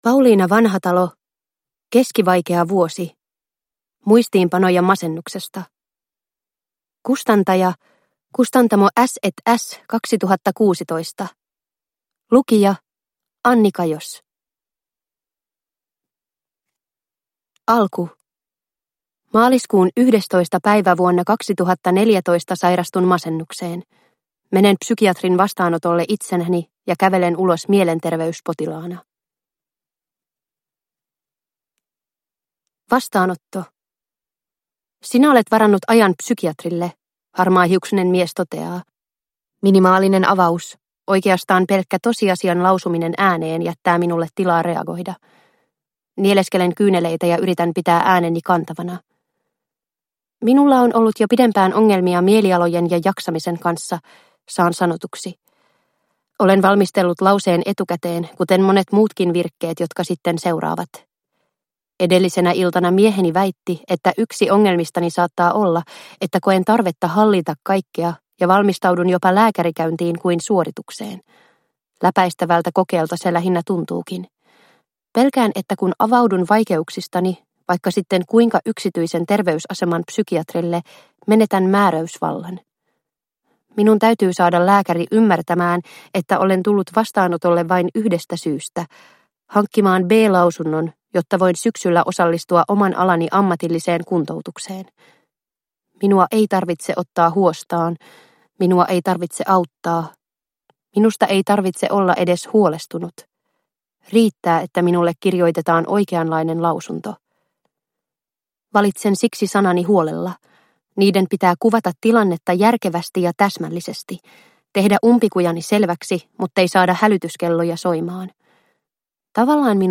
Keskivaikea vuosi – Ljudbok – Laddas ner